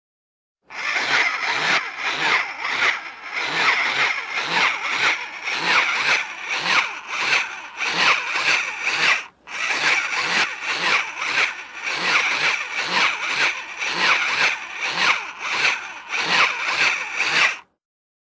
BSG FX - Mechanical Daggit walking, motor
BSG_FX_-_Mechanical_Daggit_walking2C_Motor.wav